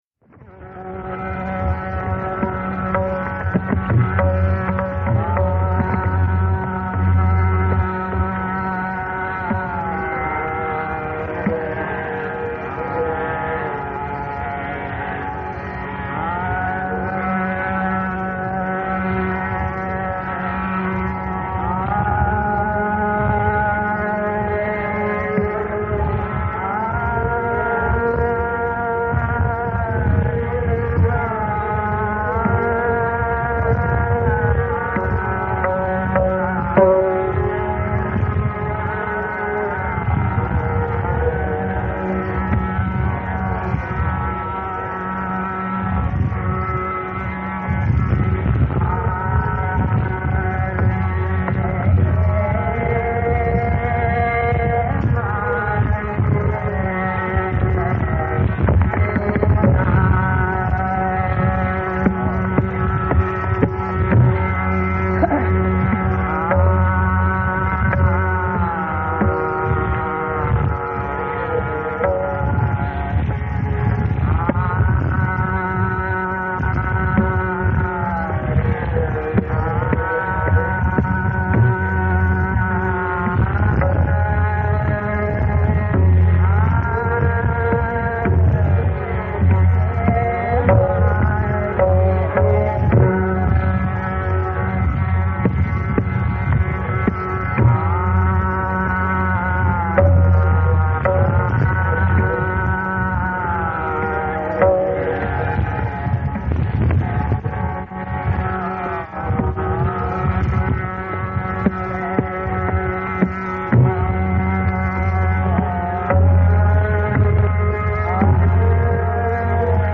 Classical Music of India